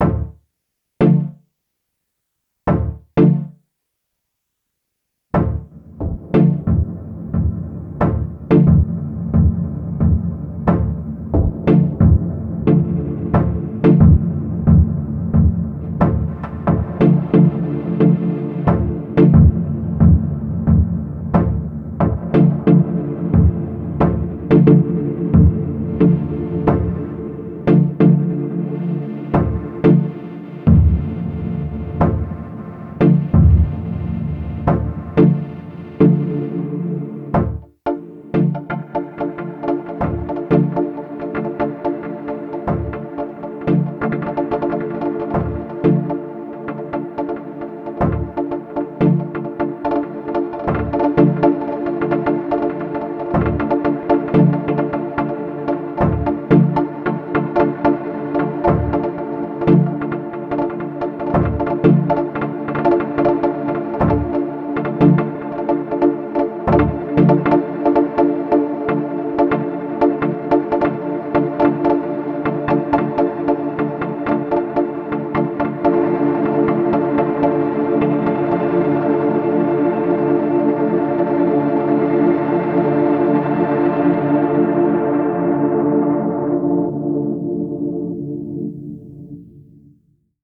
2 bar dry dub stab from Digitone. The rest is Microcosm.